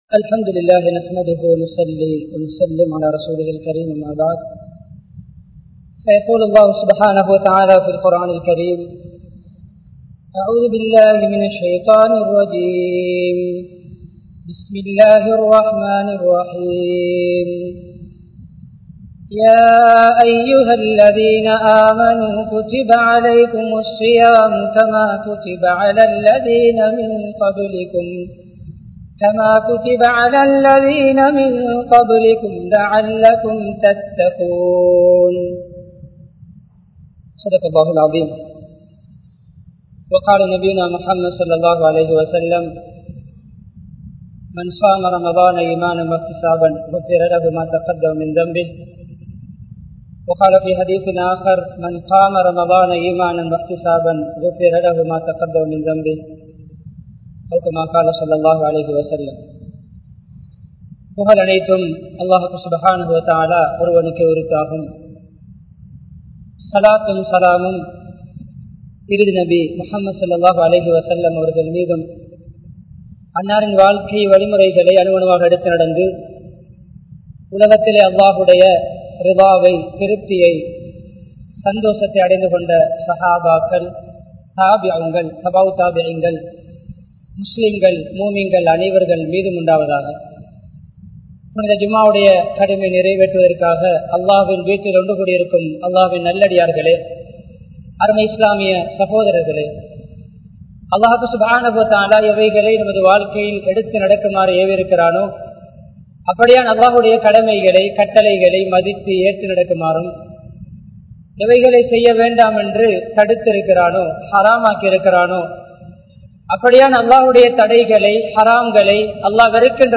Ramalaanai Sirappaaha Kalippoam (ரமழானை சிறப்பாக கழிப்போம்) | Audio Bayans | All Ceylon Muslim Youth Community | Addalaichenai
Colombo 12, Aluthkade, Muhiyadeen Jumua Masjidh